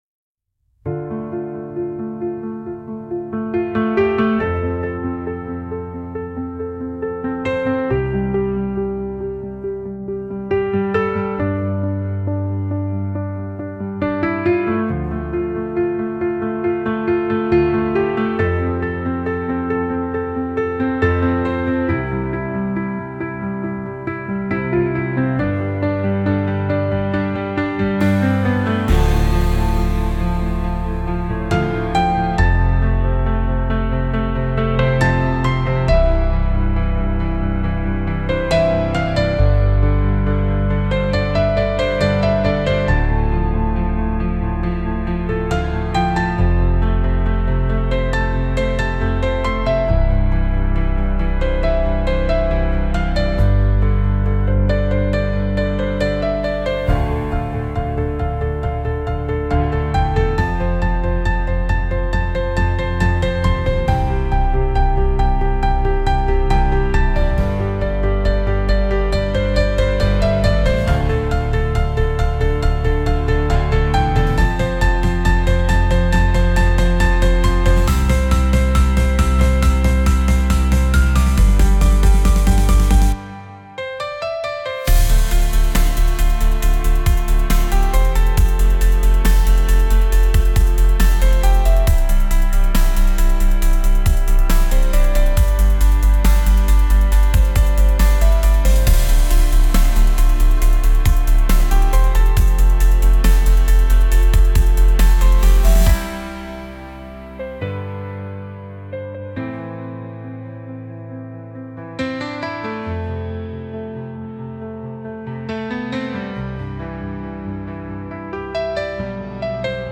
Lyrics: (house)